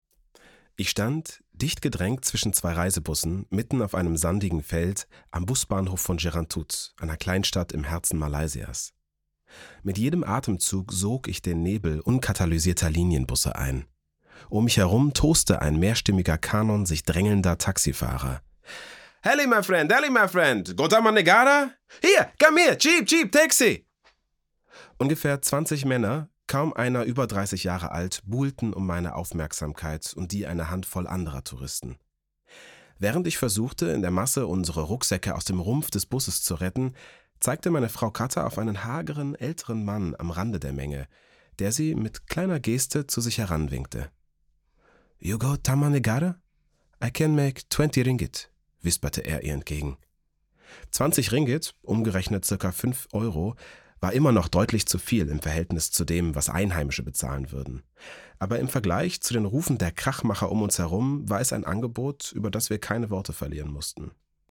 Podcast-Feature
Hörbuchähnliches Feature mit erzählenderem Sprech als beim Hörbuch
Meine stimmcharakteristik ist warm, klar und nahbar.